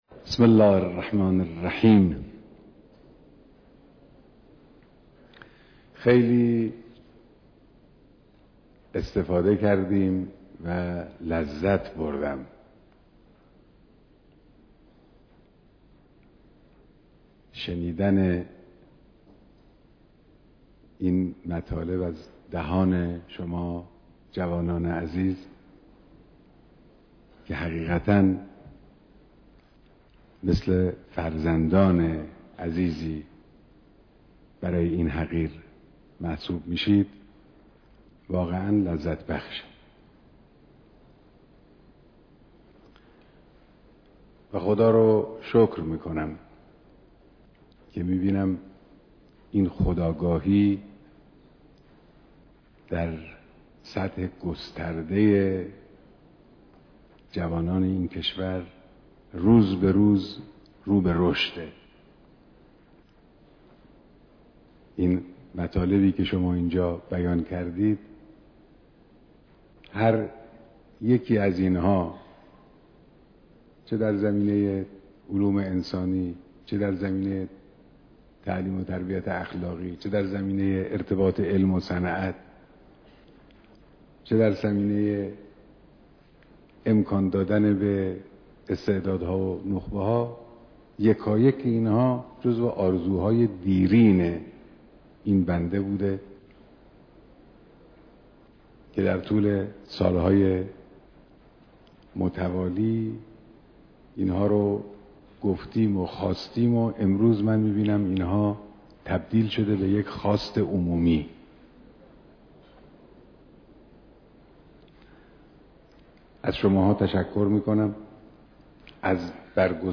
دیدار صدها نفر از افتخار آفرينان المپيادهاي كشوري، دانشجويي، دانش آموزی و جهاني